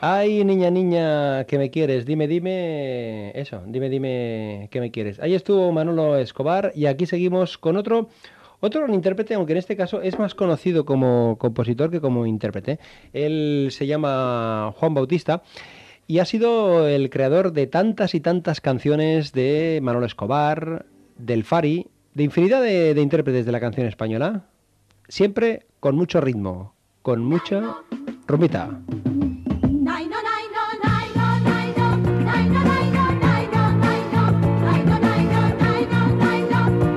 Presentació d'un tema musical
Entreteniment